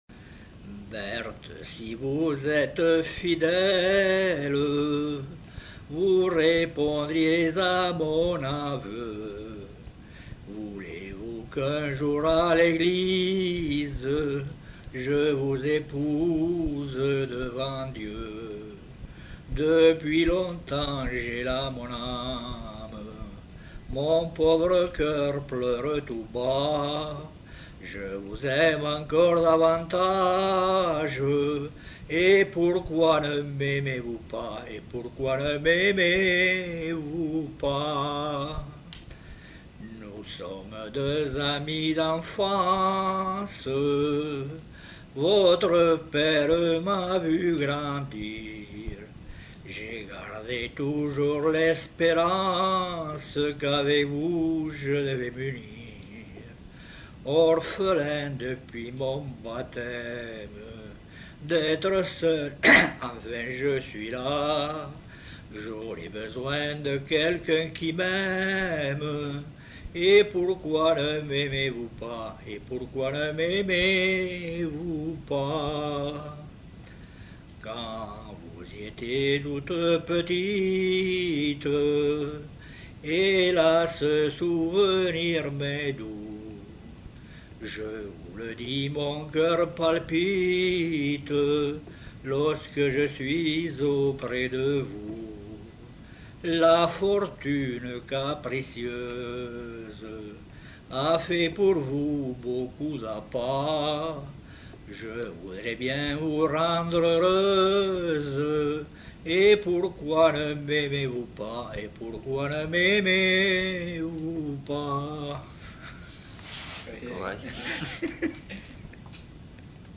Genre : chant
Effectif : 1
Type de voix : voix d'homme
Production du son : chanté
Description de l'item : version ; refr.